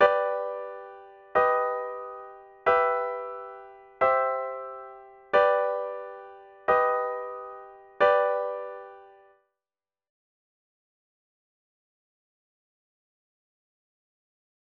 G augmented chord progression
G augmented progression
G-Augmented-Progression.mp3